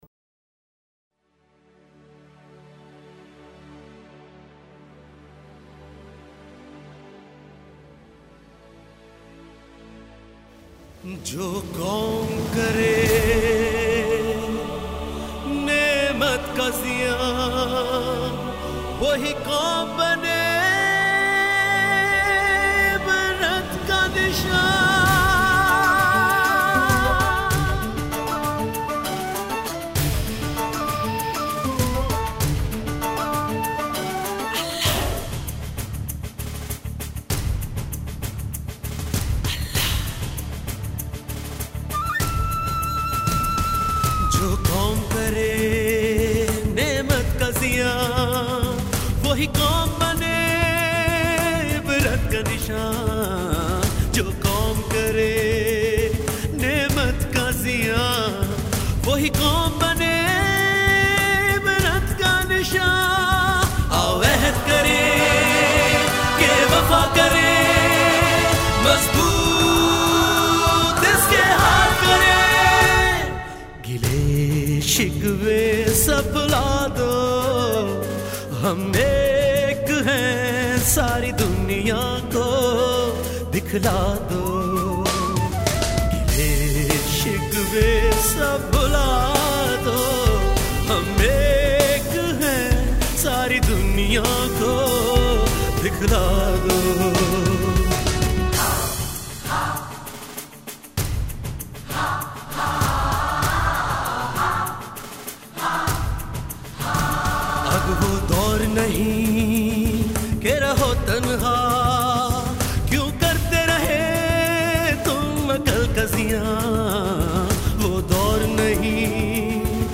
Hamd, Naat & Kalam, اردو urdu